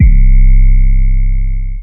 Gamer World 808 2.wav